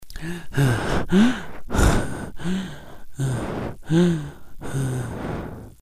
Ambient sound effects
Descargar EFECTO DE SONIDO DE AMBIENTE CANSADO - Tono móvil